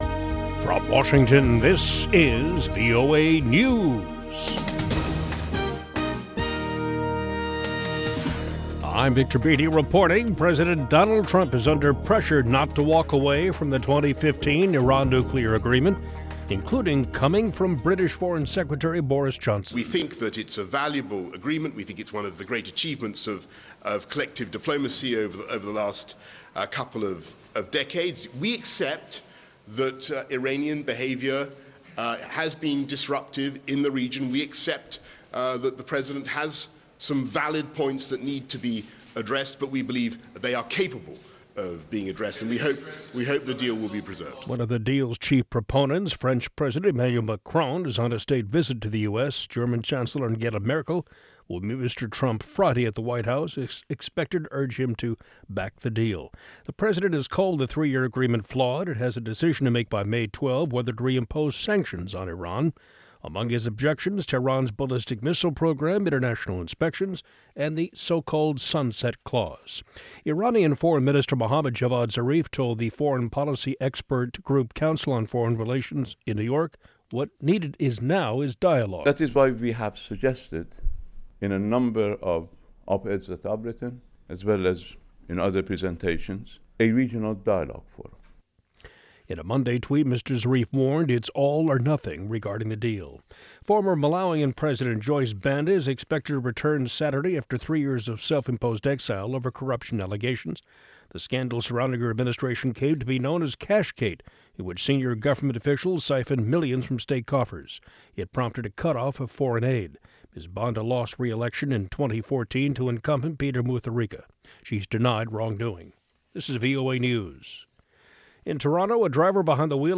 Each morning, Daybreak Africa looks at the latest developments on the continent, starting with headline news and providing in-depth interviews, reports from VOA correspondents, sports news as well as listener comments.